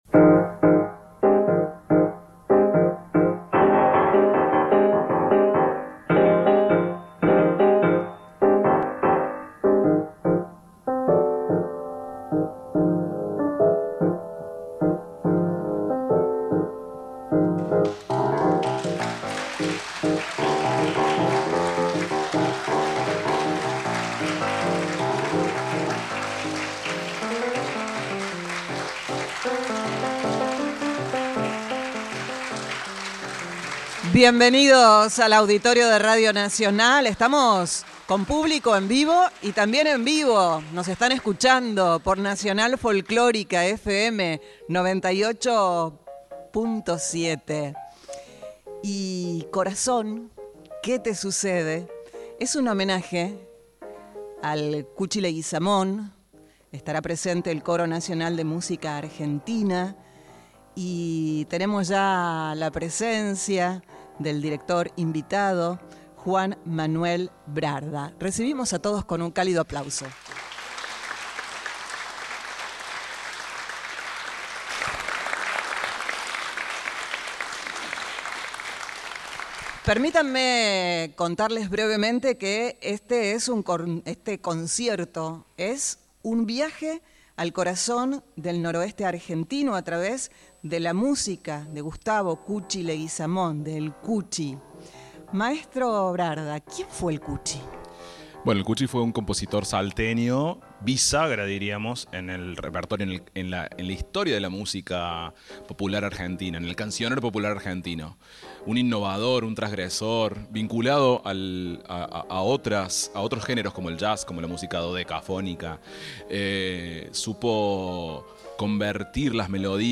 Cuatro solistas
en el Auditorio de Radio Nacional
soprano
contralto
tenor
barítono
pianista
guitarrista
percusionista